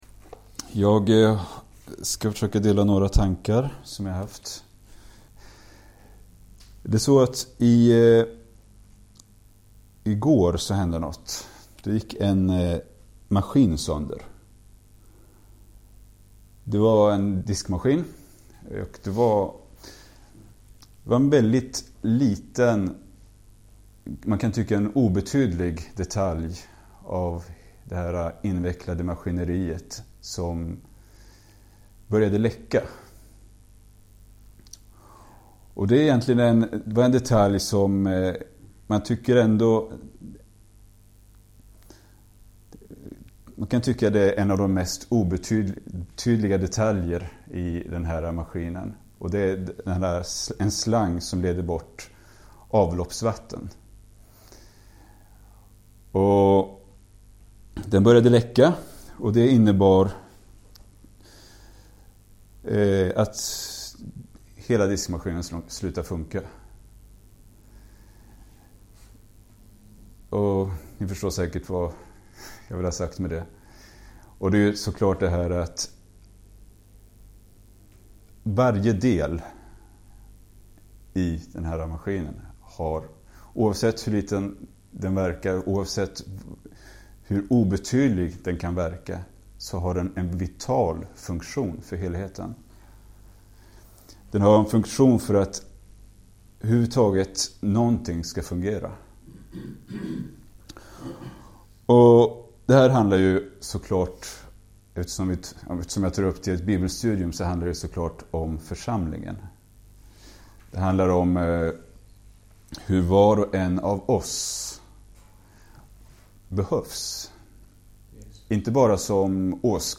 Liveupptagning